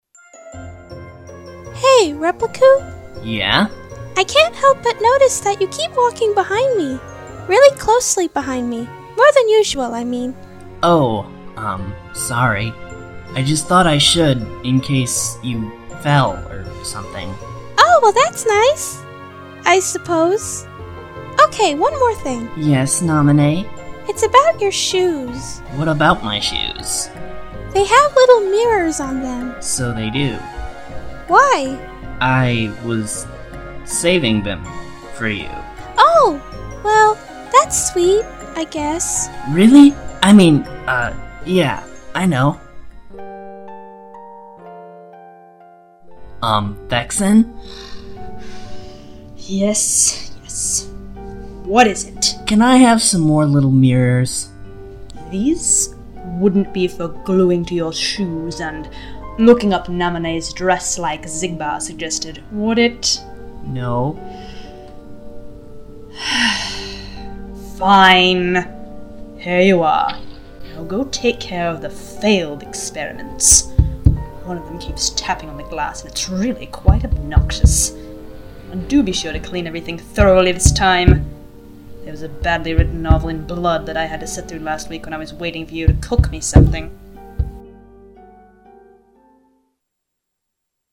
Short Dramas